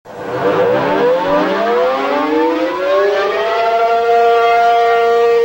Air Raid Sirens
They also make the LOUDEST siren currently, the T-135. This siren kicks tail at 135 decibles.